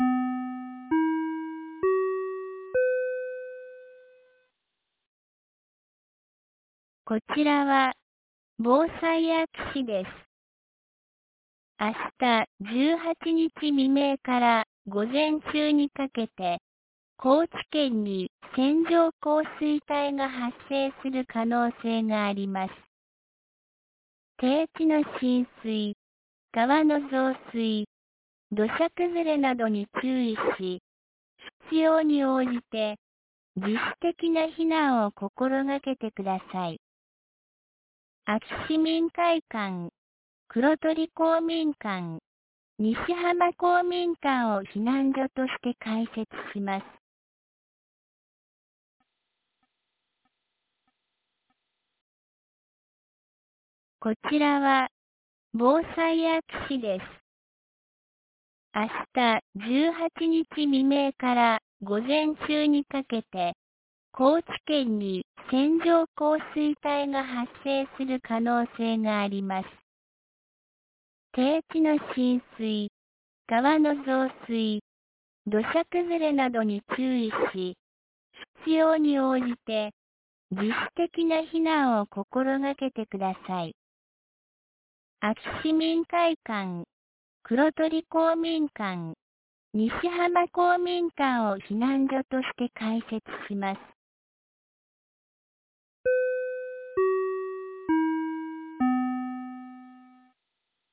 2024年06月17日 16時33分に、安芸市より安芸へ放送がありました。